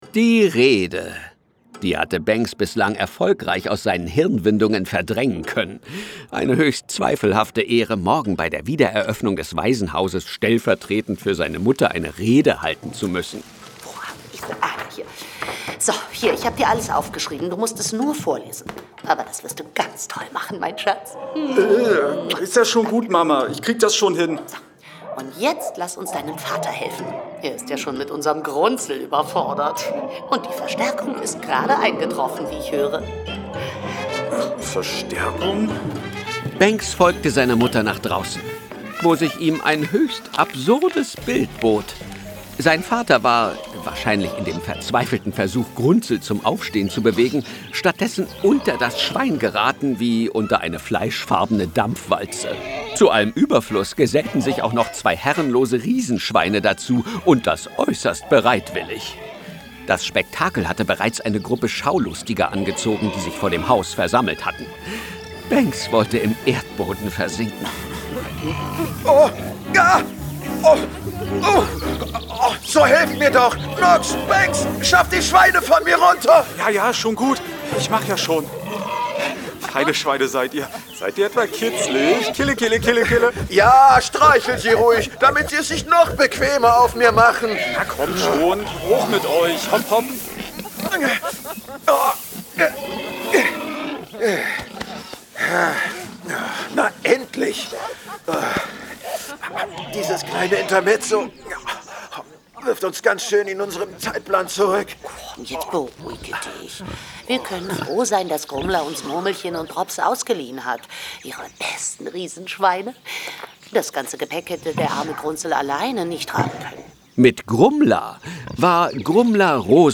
Benx und die Rückkehr des Enderdrachen Folge 02. Hörspiel.